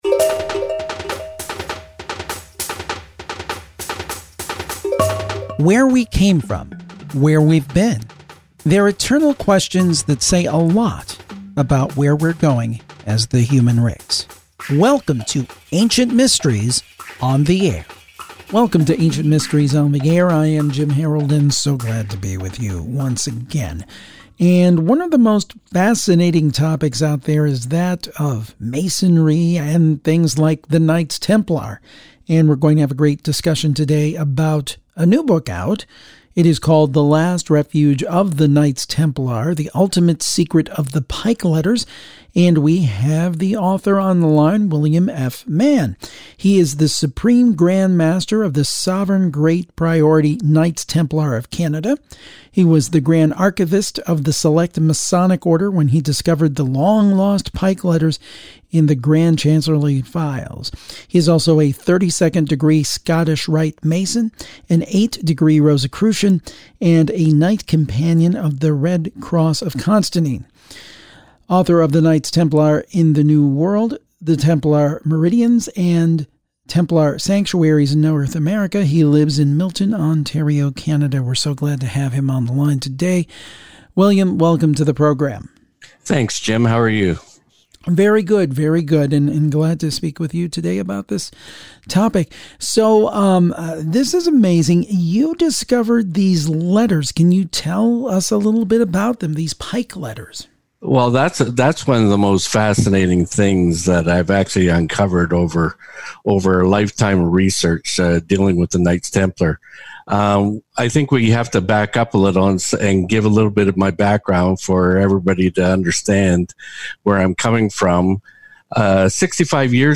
Here's an interesting interview with a high ranking Masonic Knight Templar.